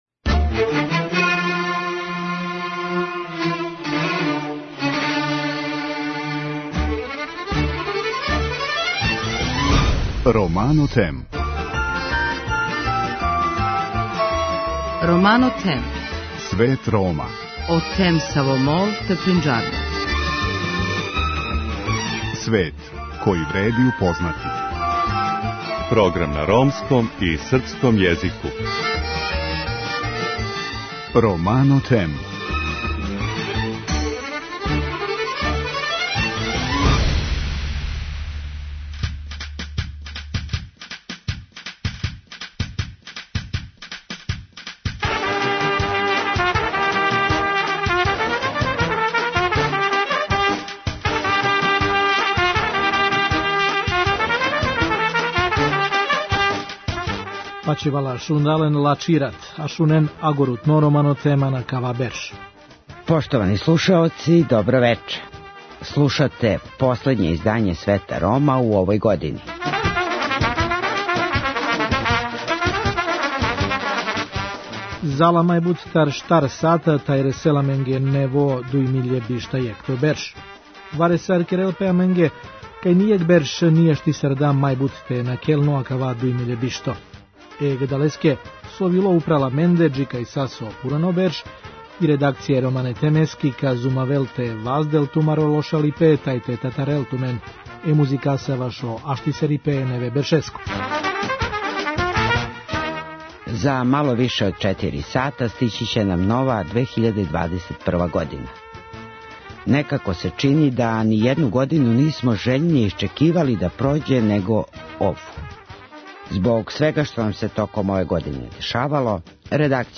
Редакција Света Рома ће музиком покушати да вам побољша расположење и загреје вас за дочек Нове 2021.године!